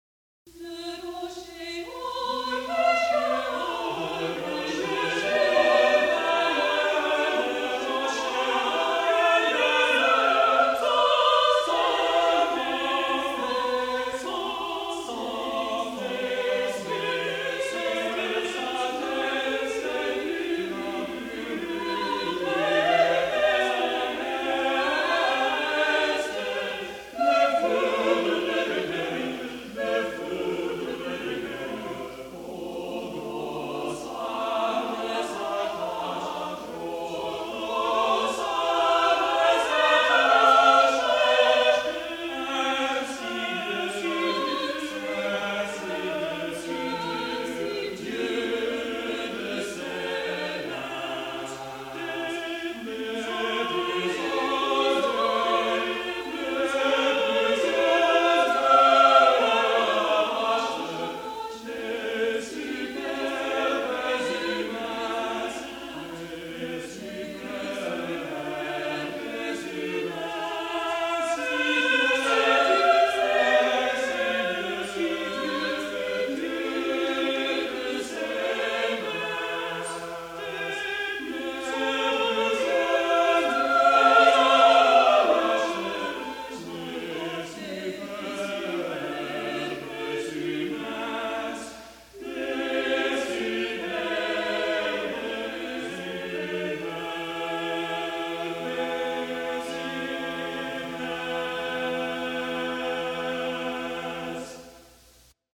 | Vocal Ensemble 'Seven Ages' 1979